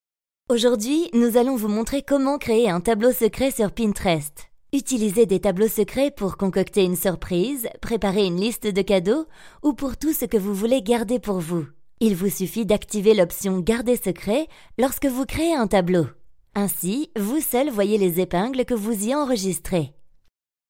French voice over since 2005 ( 16 years experience) and i have my own home studio with professional quality. My voice is young and fresh and can match your projects for tv and radio commercials, corporate videos, e-learning, IVR, but also dubbing and voice over for documentary
Sprechprobe: eLearning (Muttersprache):